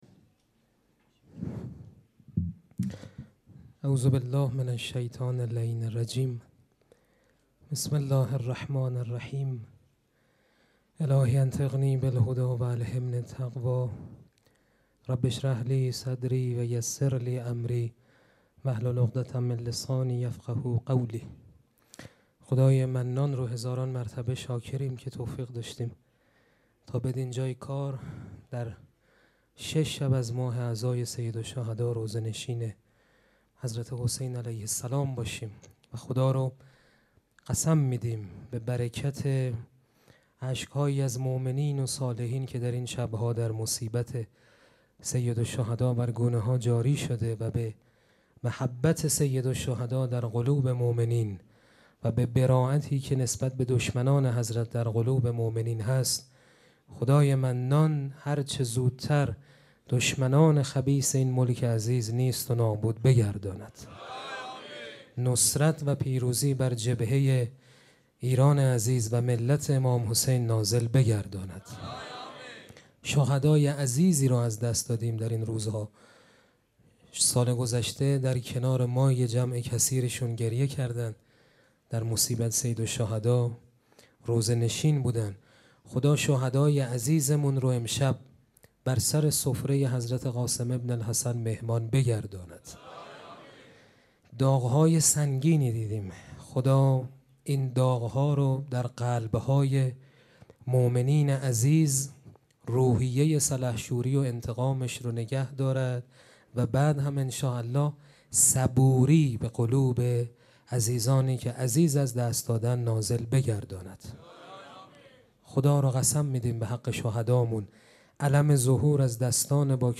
سخنرانی
مراسم عزاداری شب ششم محرم الحرام ۱۴۴۷ سه‌شنبه ۱۰تیر۱۴۰۴ | ۵ محرم‌الحرام ۱۴۴۷ هیئت ریحانه الحسین سلام الله علیها